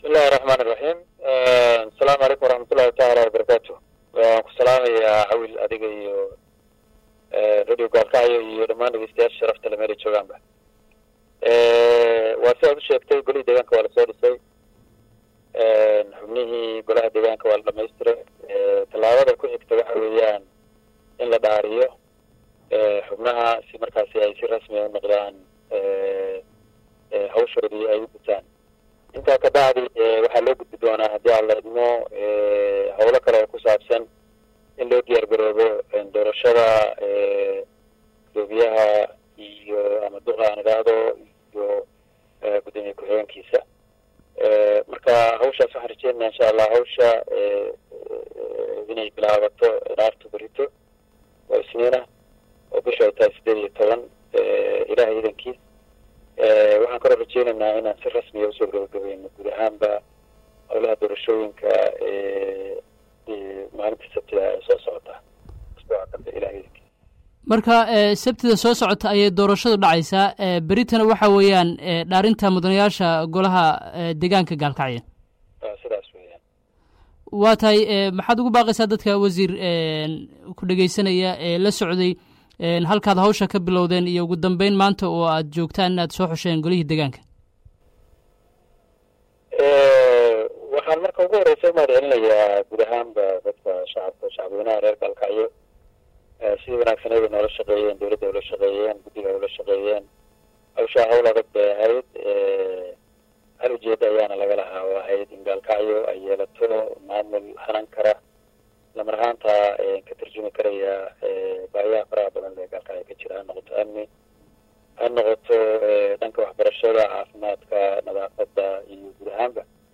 17 July 2016 (Puntlandes) Gudoomiyaha Gudiga Hagaajinta iyo Dhismaha Golaha Deegaanka Degmada Gaalkacyo isla markaasna ah Wasiirka Deegaanka Dr. Cali Cabdulaahi Warsame ayaa maanta ka hadlay halka ay marayso hawlihii Gudigu u xilsaarnayeen oo dhawaan ay soo afjarayaan.